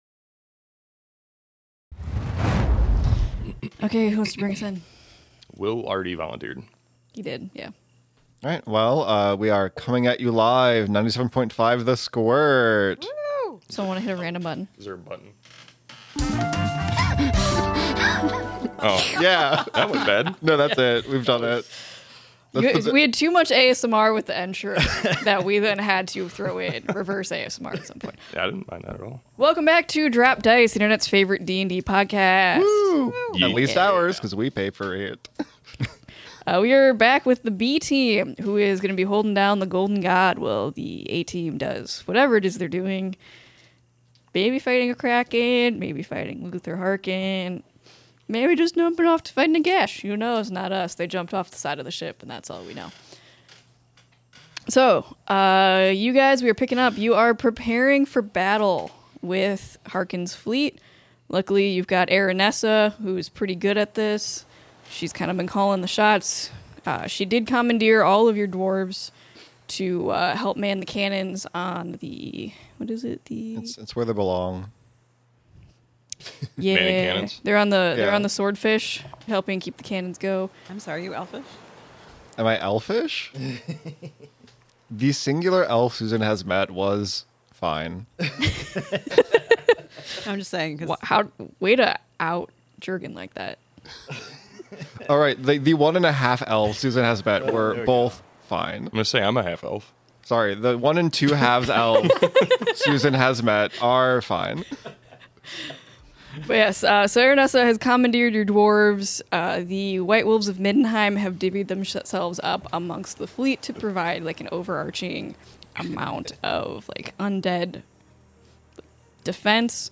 Audio Drama